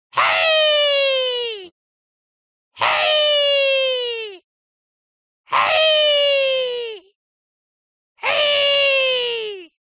Звуки фэнтези
Восторженные карлики хееей